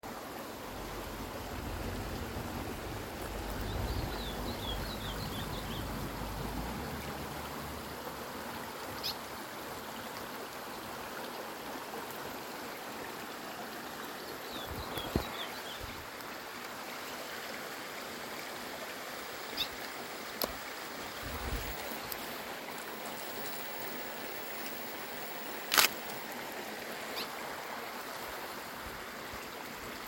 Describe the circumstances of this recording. Condition: Wild